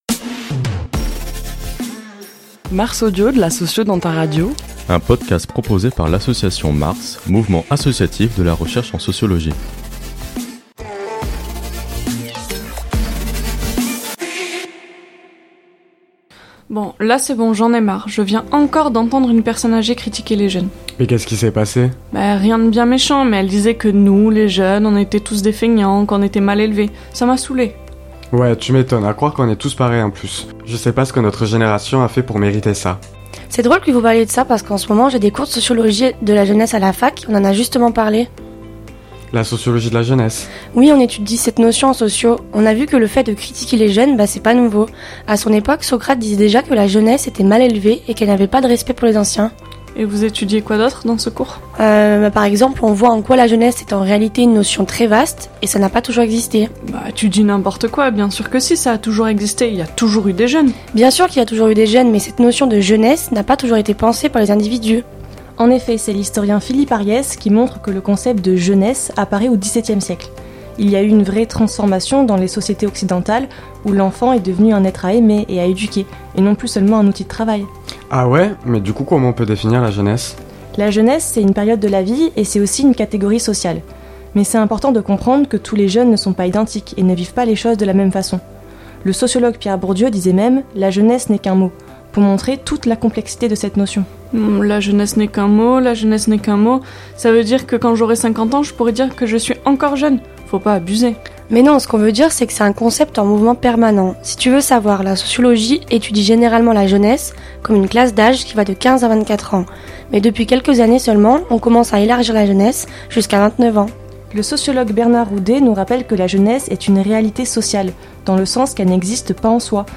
Ce mois-ci, MARS Audio vous invite à découvrir la jeunesse à travers un court dialogue fictif.